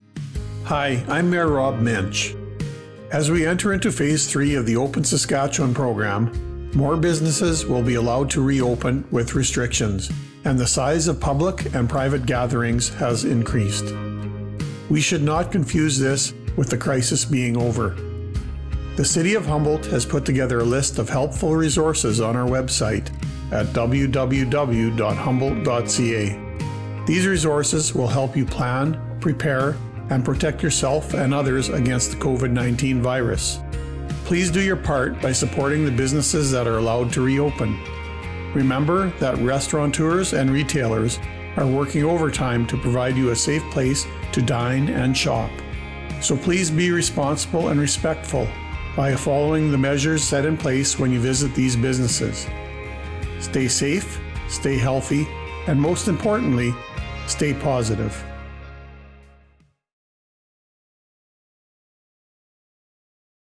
A message from Mayor Muench:
Mayors_Address_Phase_Three.wav